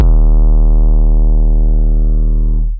808s
PBS - (808) Oweeeee.wav